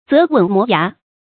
澤吻磨牙 注音： ㄗㄜˊ ㄨㄣˇ ㄇㄛˊ ㄧㄚˊ 讀音讀法： 意思解釋： 猶言齜牙咧嘴。